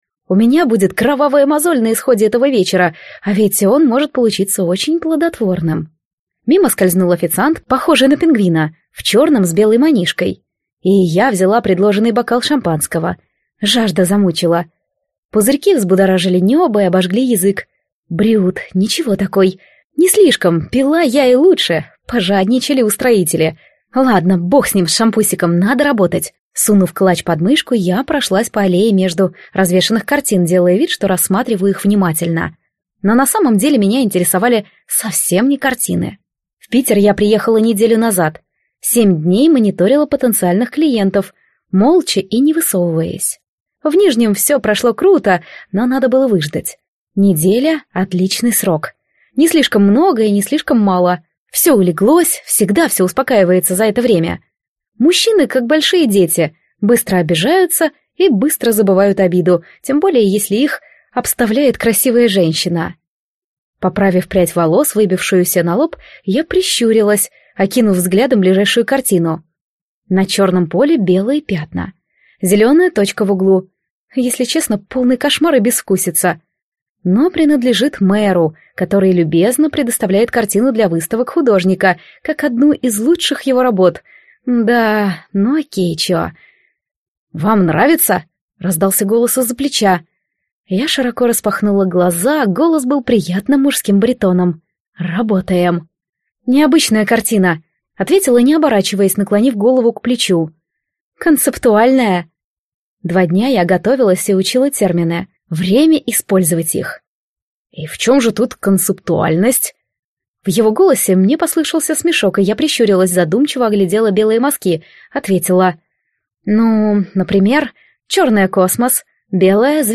Аудиокнига Профессионально беременна | Библиотека аудиокниг
Прослушать и бесплатно скачать фрагмент аудиокниги